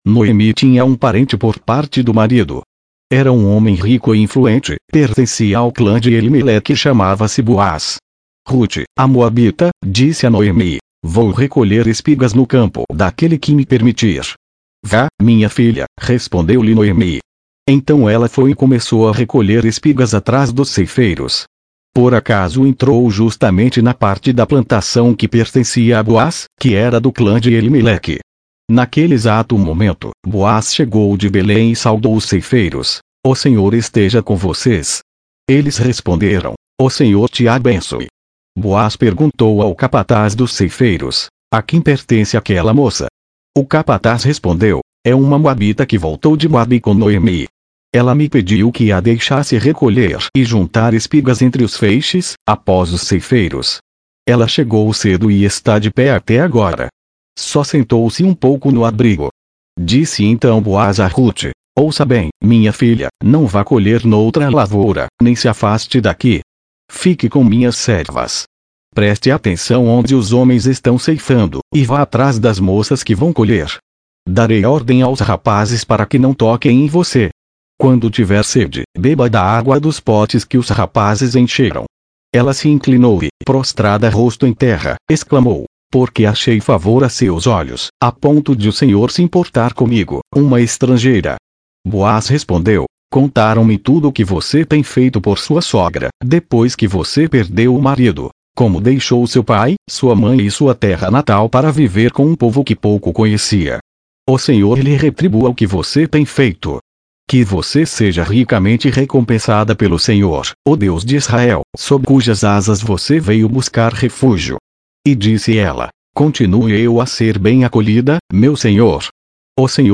Leitura na versão Nova Versão Internacional - Português